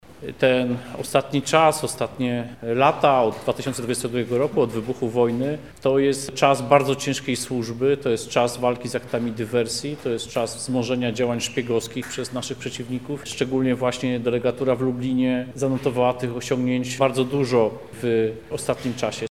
Tomasz Siemoniak– mówi minister Tomasz Siemoniak, Koordynator Służb Specjalnych.